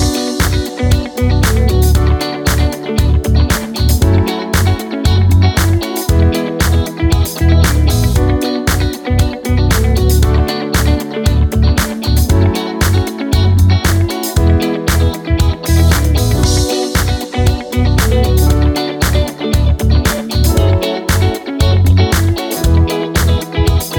No Guitars Pop (2010s) 4:17 Buy £1.50